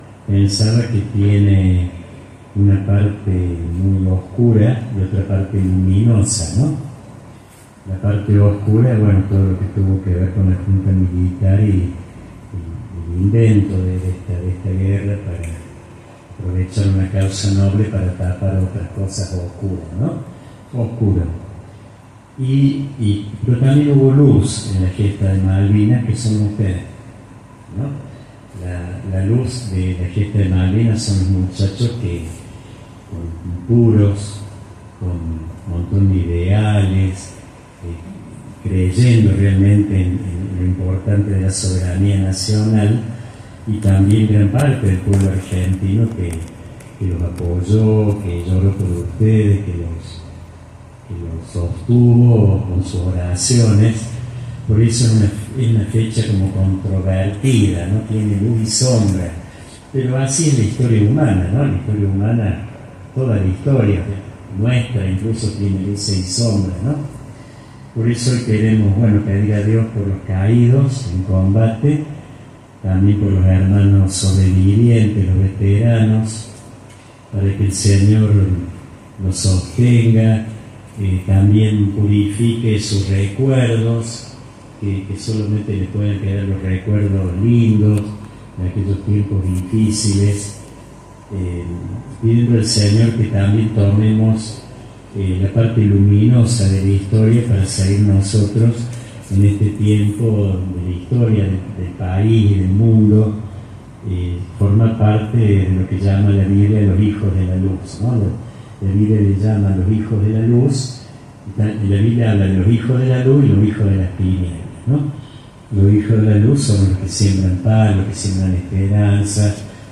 La vigilia, conocida como “Noche de Gloria”, se realizó el miércoles por la noche en el salón Cura Monguillot.
bendición